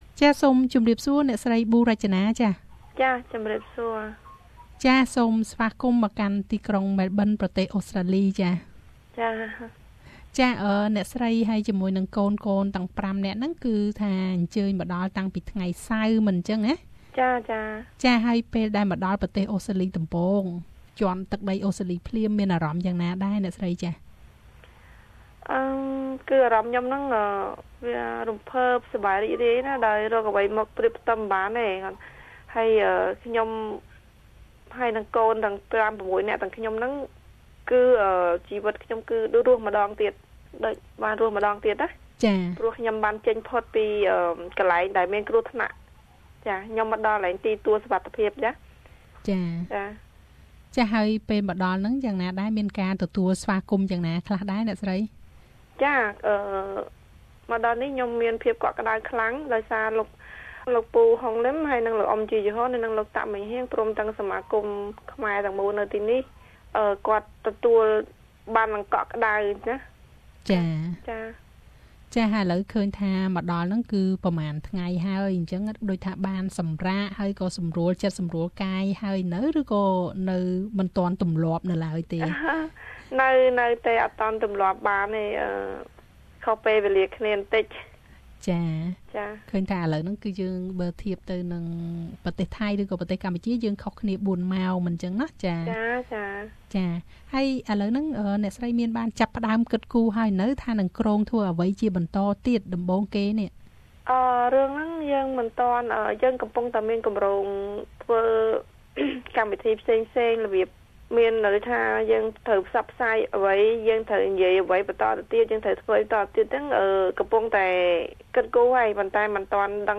សូមស្តាប់នូវបទសម្ភាសន៍ដូចតទៅ។ READ MORE មតិពលរដ្ឋខ្មែរនៅអូស្ត្រាលី ស្វាគមន៍ការមកតាំងទីលំនៅថ្មី របស់ភរិយា និងកូនៗ លោក កែម ឡី Share